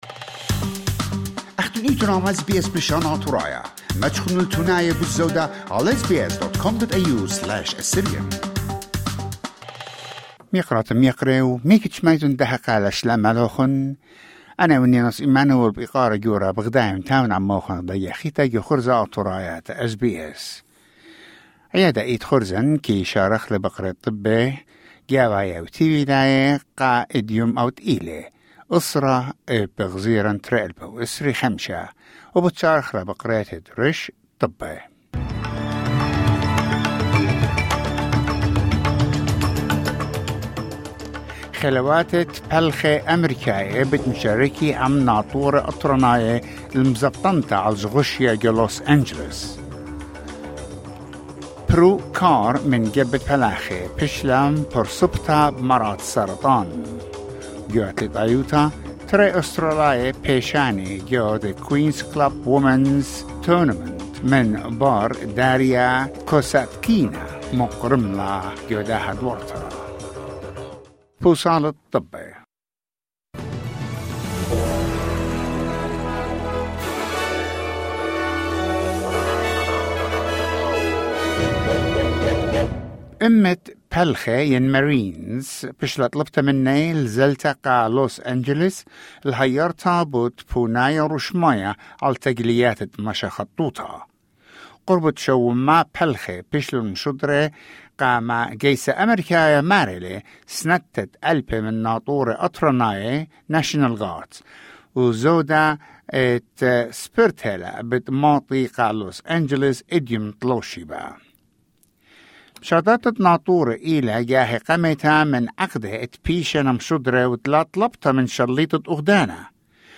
News bulletin: 10 June 2025